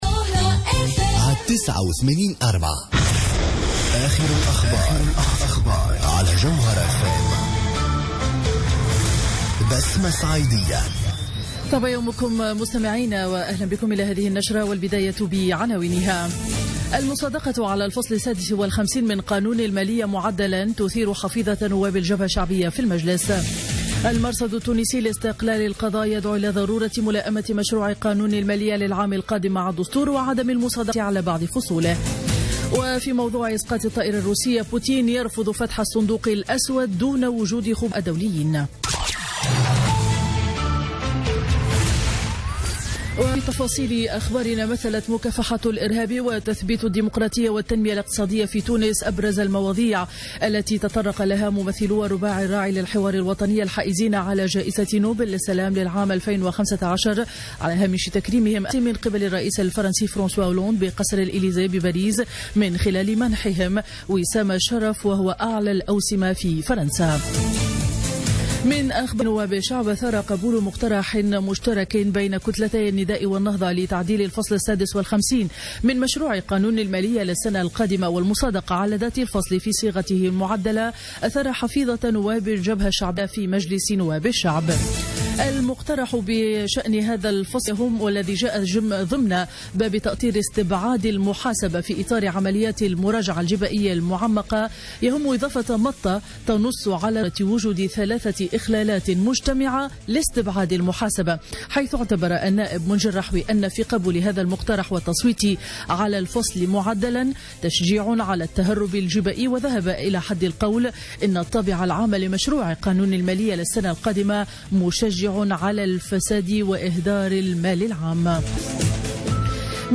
نشرة أخبار السابعة صباحا ليوم الأربعاء 9 ديسمبر 2015